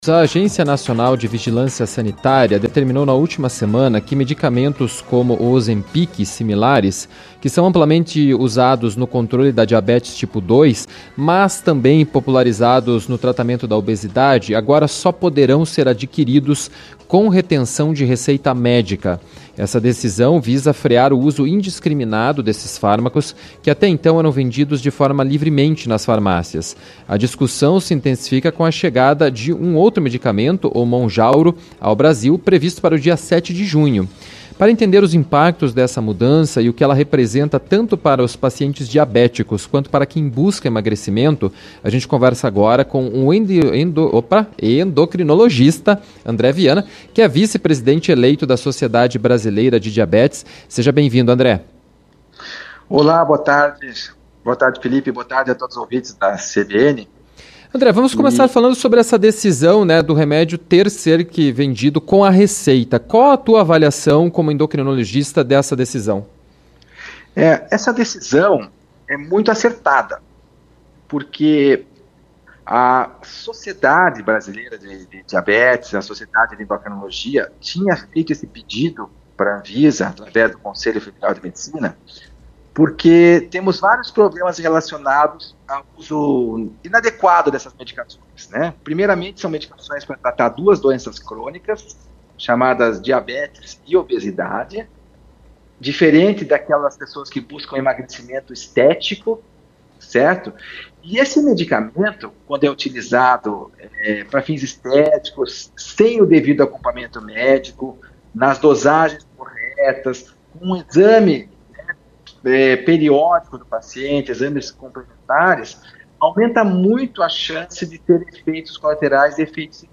conversou com o endocrinologista